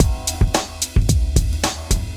110LOOP B8-R.wav